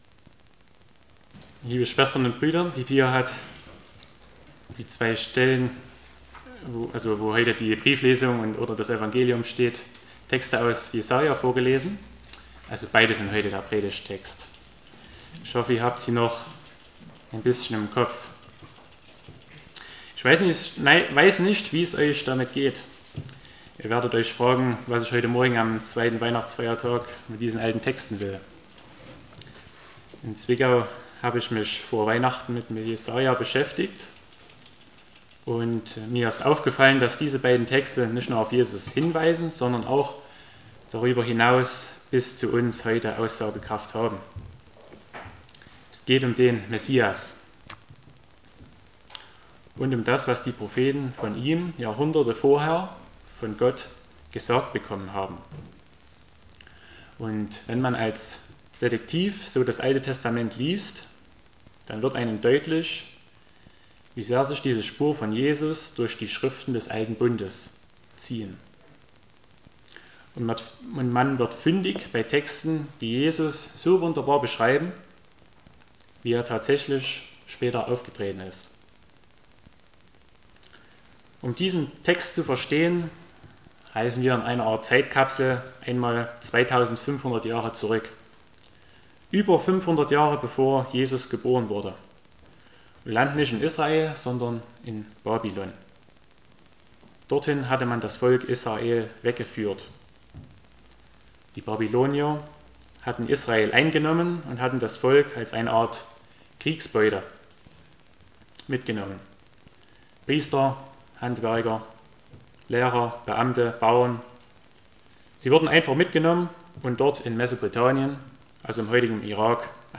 Eine Gastpredigt